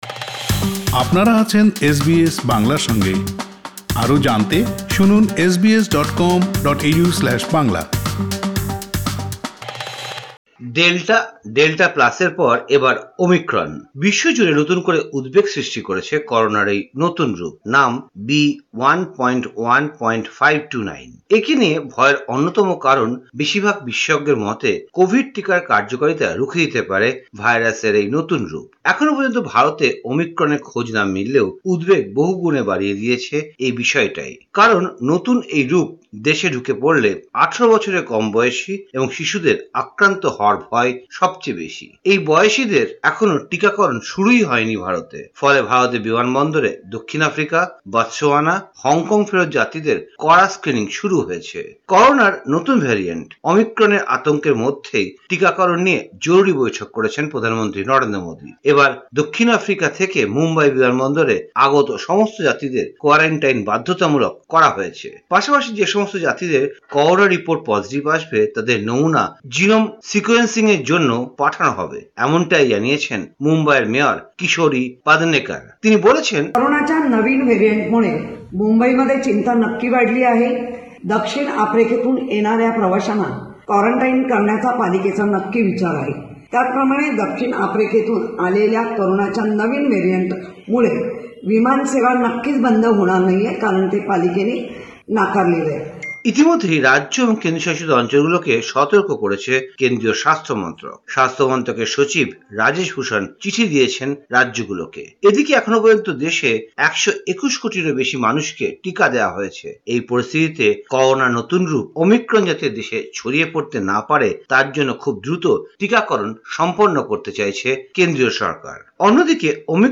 ভারতীয় সংবাদ: ২৯ নভেম্বর ২০২১
কলকাতা থেকে